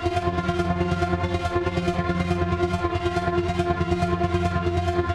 Index of /musicradar/dystopian-drone-samples/Tempo Loops/140bpm
DD_TempoDroneB_140-F.wav